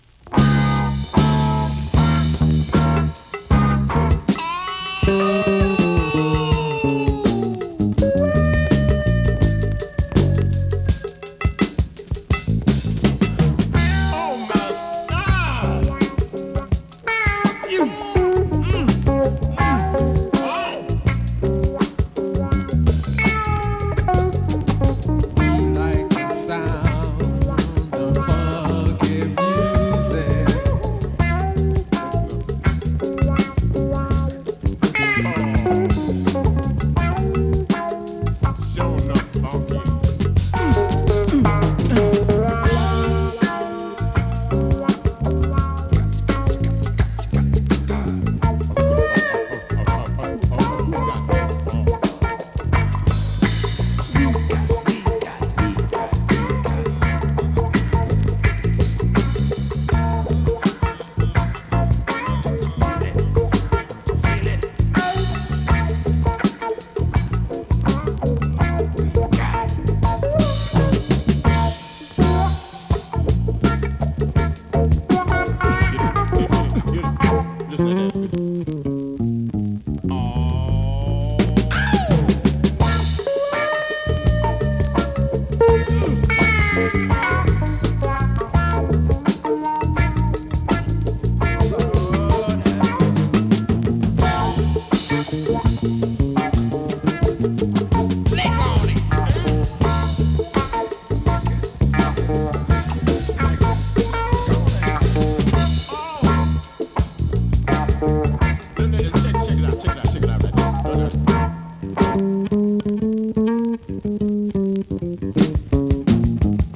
類別 R&B、靈魂樂
Great deep funk !!
試聴 (実際の出品物からの録音です)